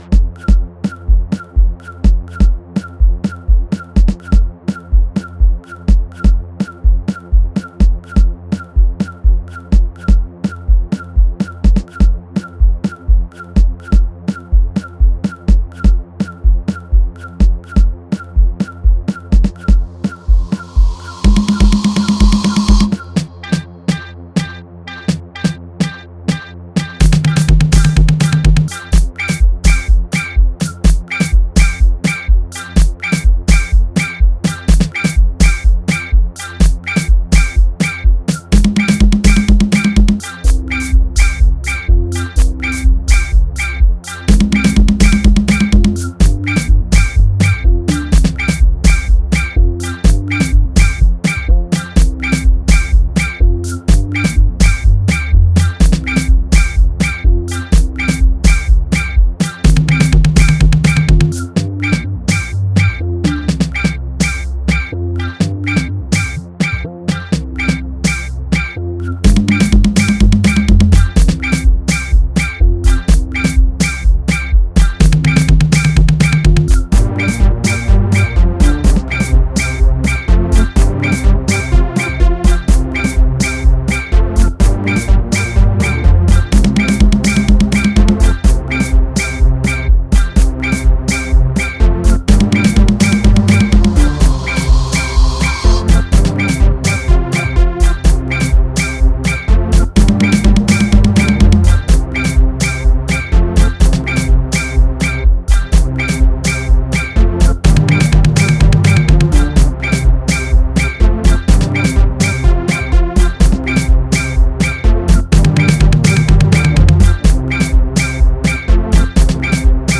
Genre : Gqom